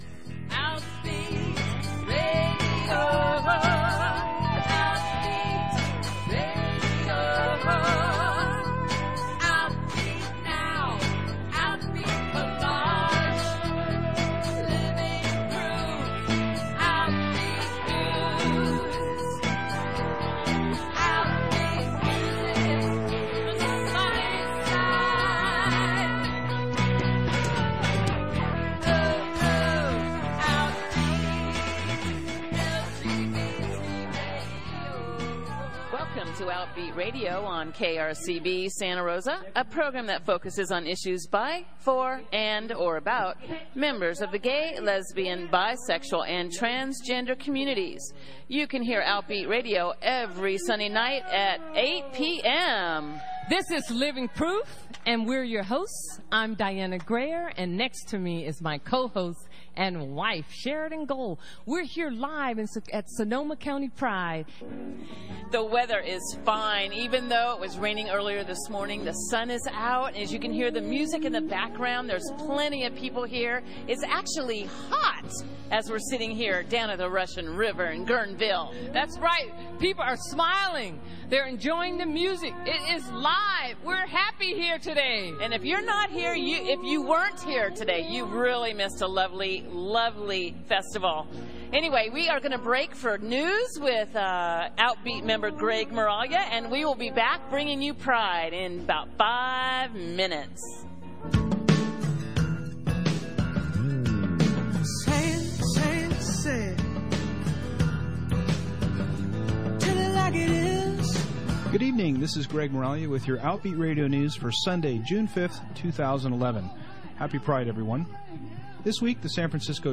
Hear the music and the sounds of pride.